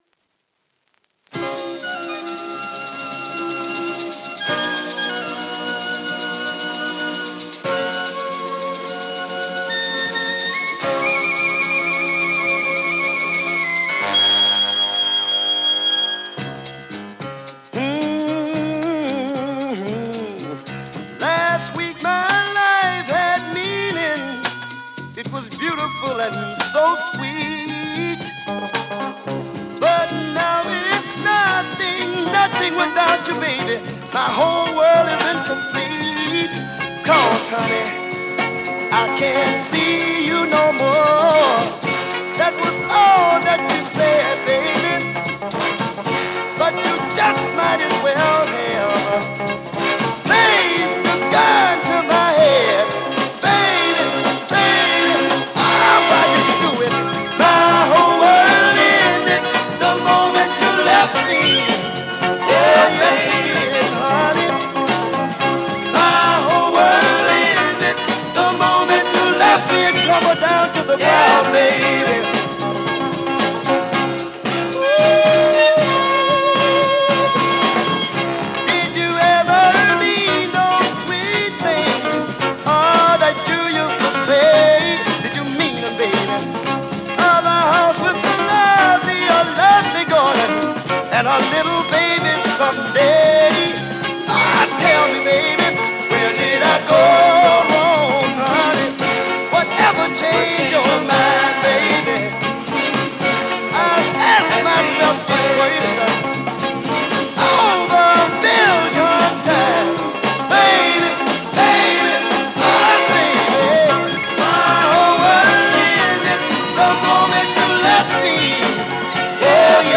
Soul Mod